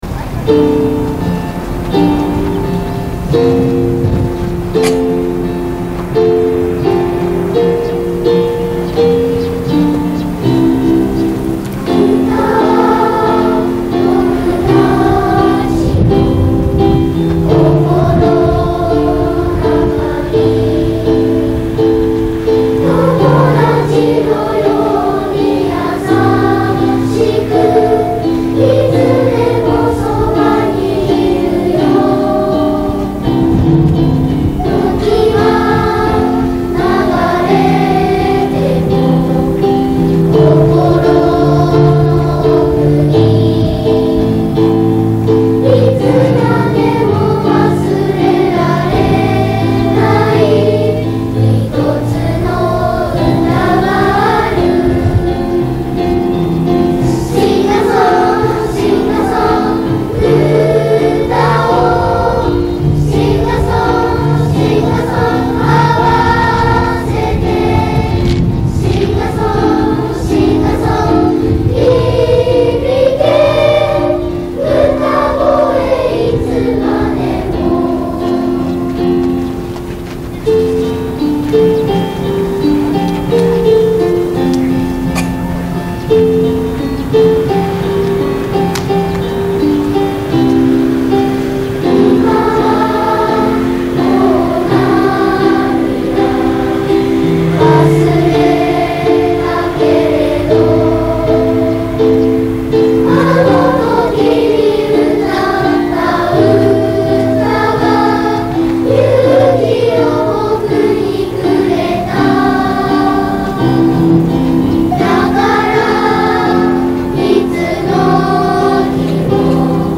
最後に会場合唱「
スクリーンには、運動場に集まり全員が心を合わせて歌っている姿が映し出されます。
大空に歌声が響き渡る、そんな全校合唱でした♪